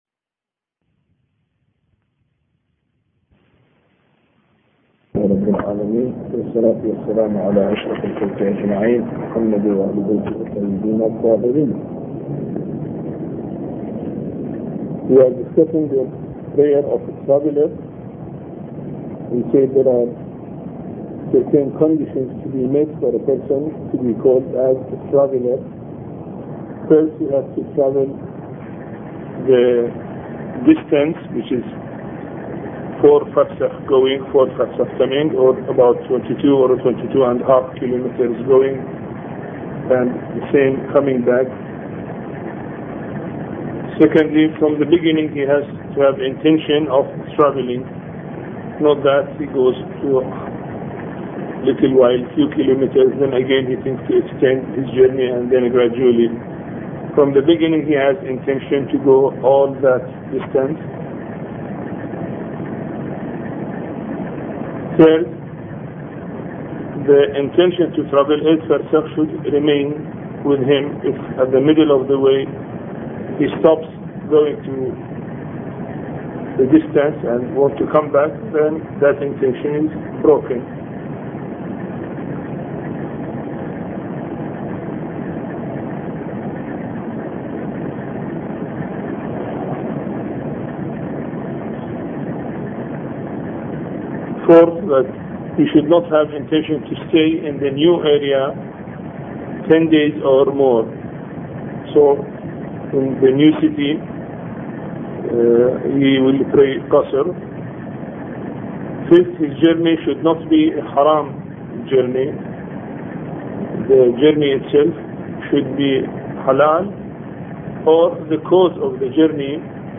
A Course on Fiqh Lecture 23